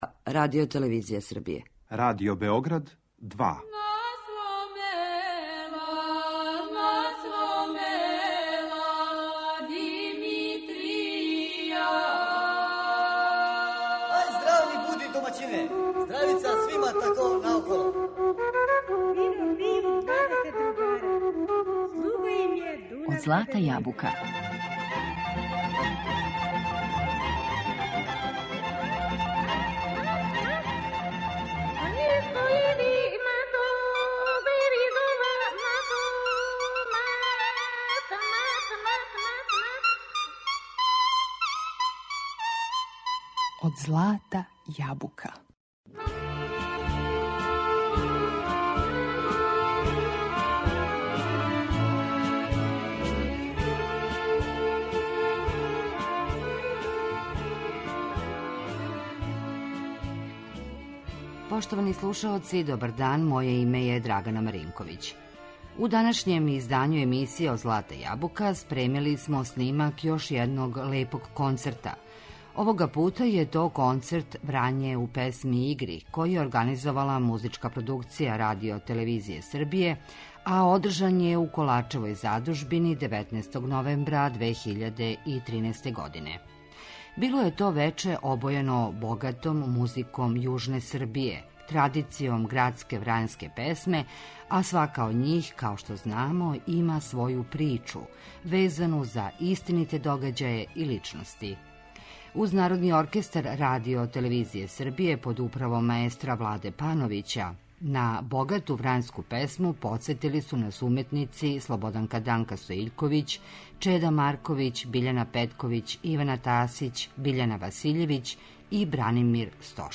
У данашњој емисији Од злата јабука слушамо снимак концерта „Врање у песми и игри” који је организовала Музичка Продукција РТС-а у Коларчевој задужбини.
Био је то концерт обојен богатом музиком јужне Србије, традицијом градске врањске песме, а свака од њих има своју причу везану за истините догађаје и личности.
Програм је водио глумац Небојша Дугалић.